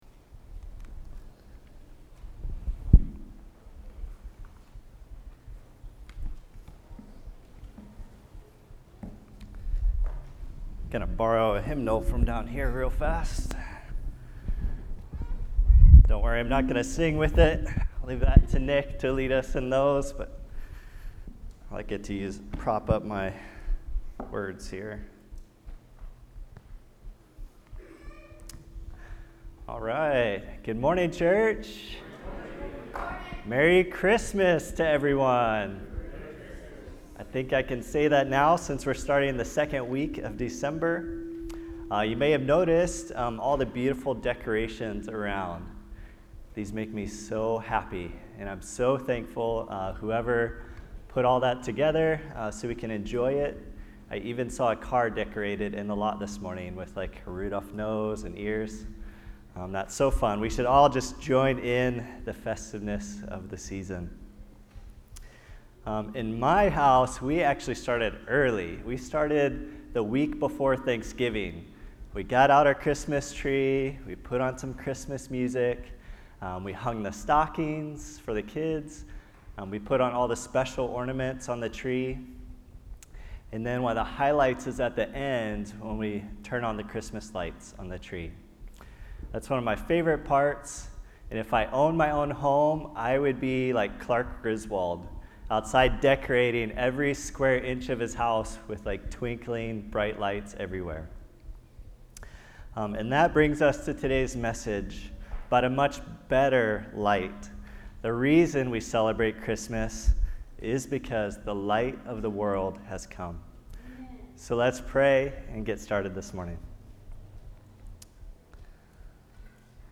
Kahului Baptist Church Sermons | Kahului Baptist Church